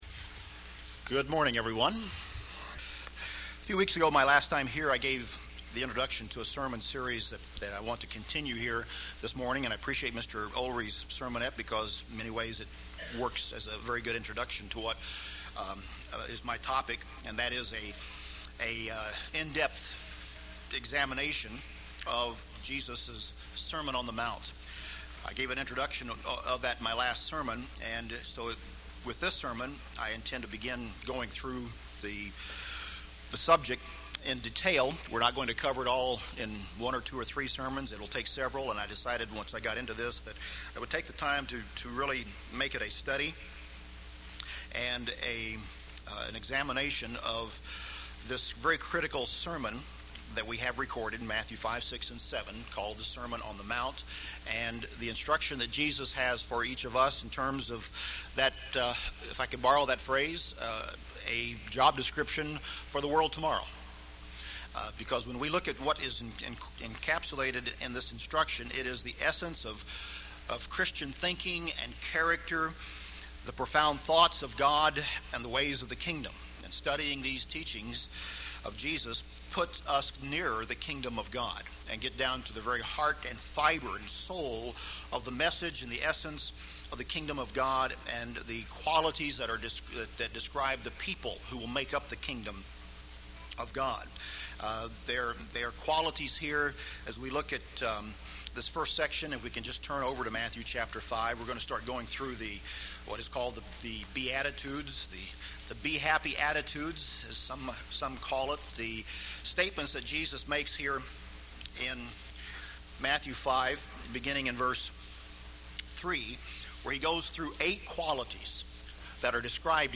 The second of a series of sermons delving in detail into Jesus' Sermon on the Mount, beginning in Matthew 5.